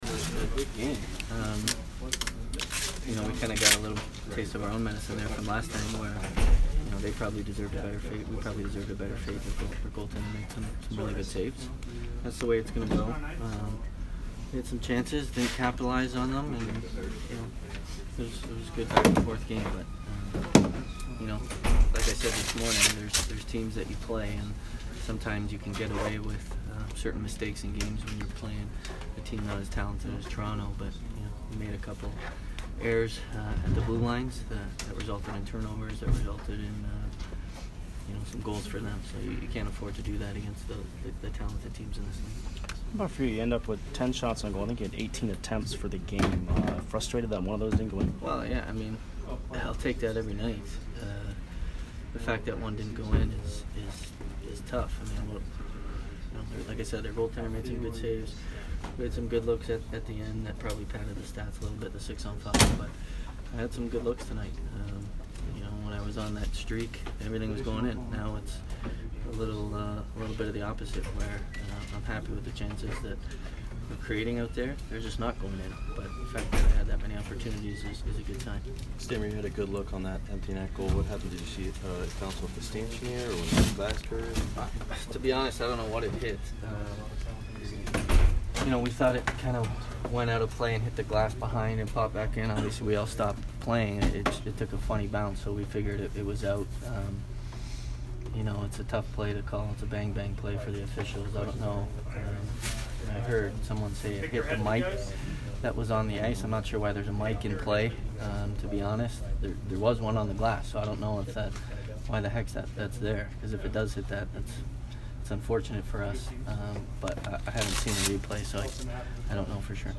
Steven Stamkos post-game 1/17